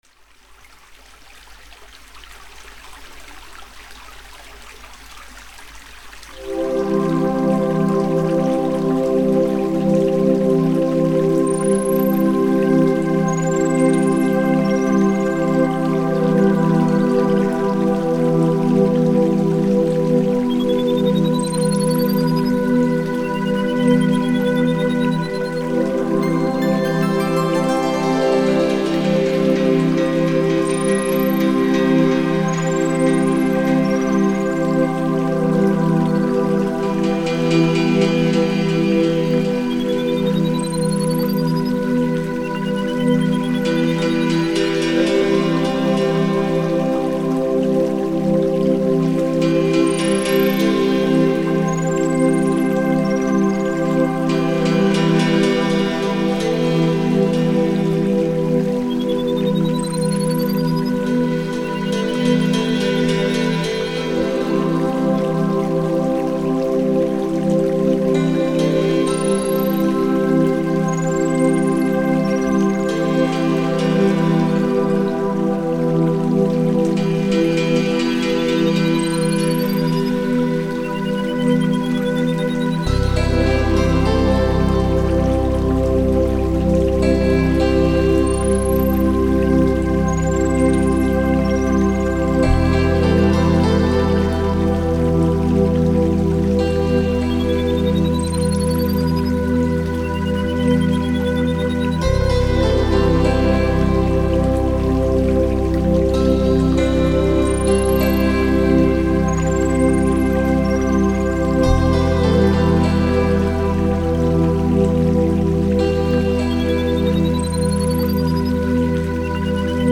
dance/electronic
Ambient
Trance